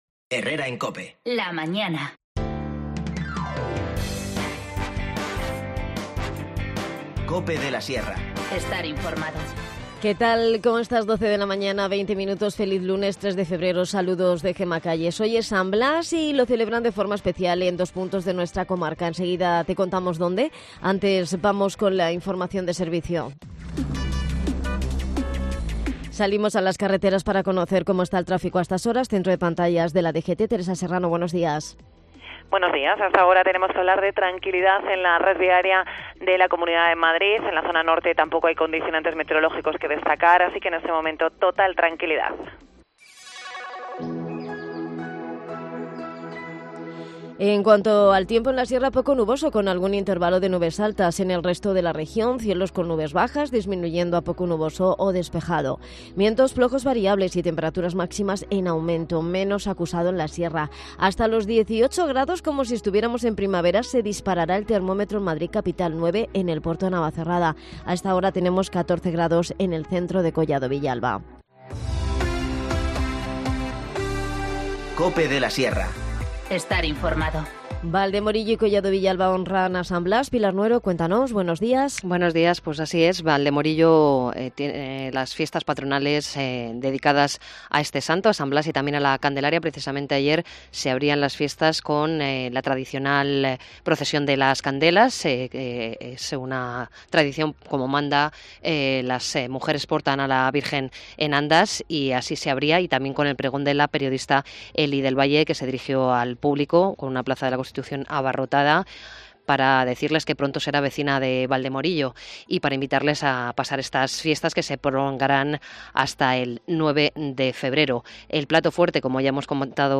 Redacción digital Madrid - Publicado el 03 feb 2020, 12:41 - Actualizado 16 mar 2023, 17:20 1 min lectura Descargar Facebook Twitter Whatsapp Telegram Enviar por email Copiar enlace El equipo ciclista Tenerife Bike Point Pizzería Española se ha presentado en San Lorenzo de El Escorial. Hablamos con algunos de sus protagonistas